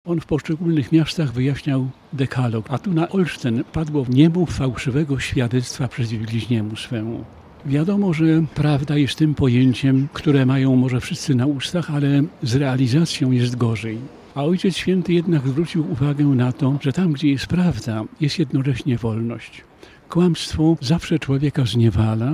– wspomina arcybiskup senior archidiecezji warmińskiej Edmund Piszcz.